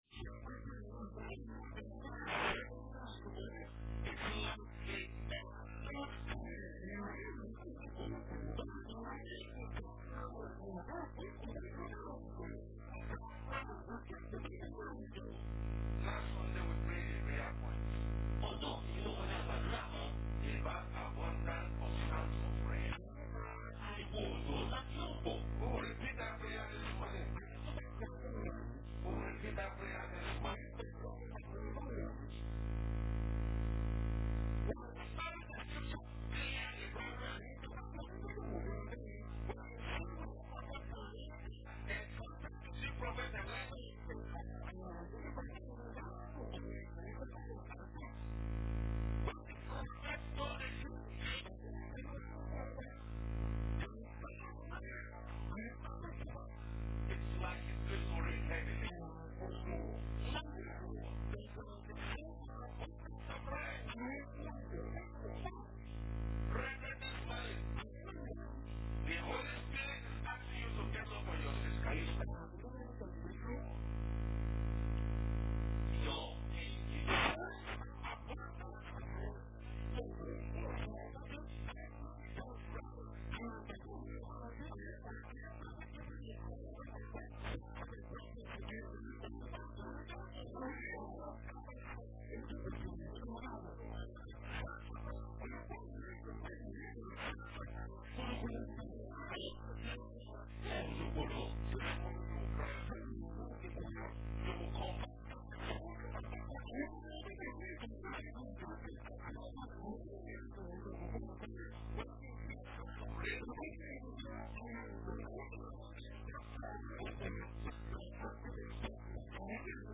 KADESHBARNEA, Part 2, is the second part of the sermon, KADESHBARNEA.
26th October 2014 Posted in Sunday Service